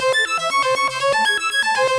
SaS_Arp03_120-A.wav